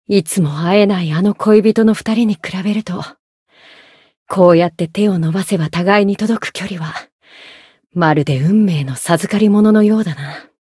灵魂潮汐-迦瓦娜-七夕（摸头语音）.ogg